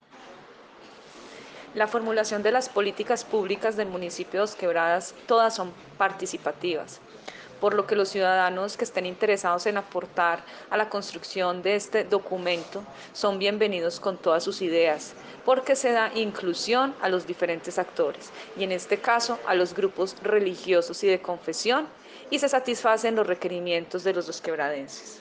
Escuchar Audio: Secretaria de Planeación, Sandra Lucía Ospina.